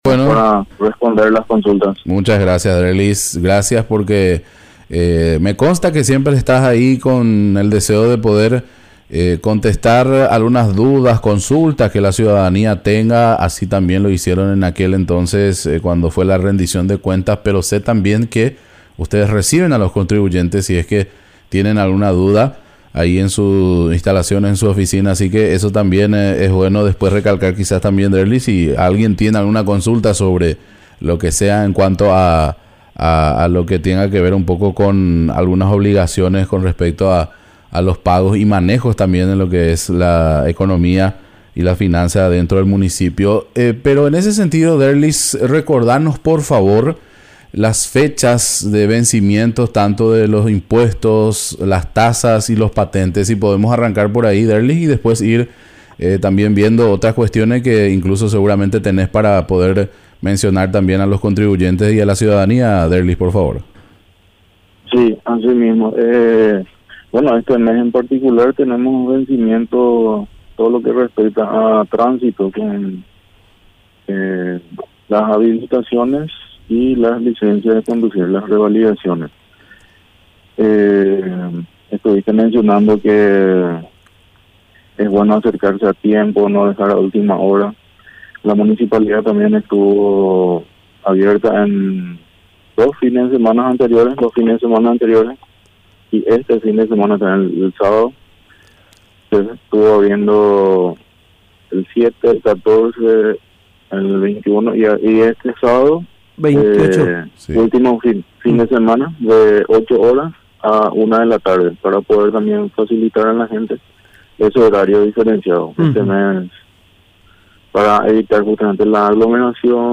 Entrevistas / Matinal 610